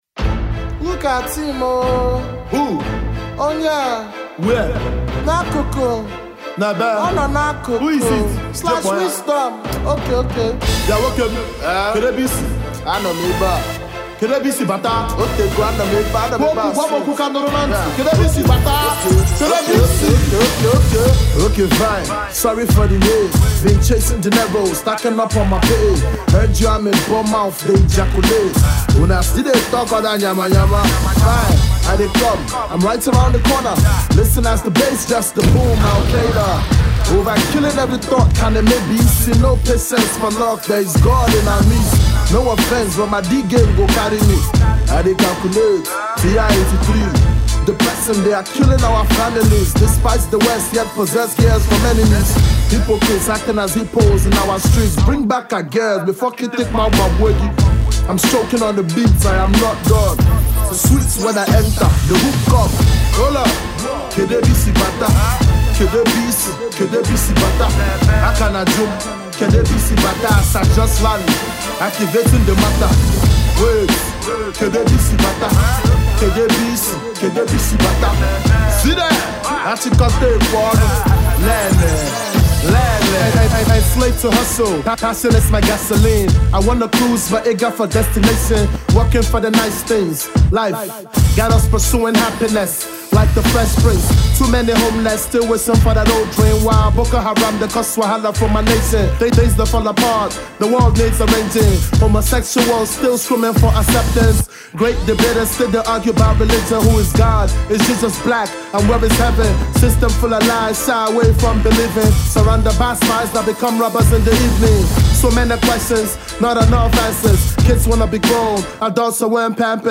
The US Based Igbo MC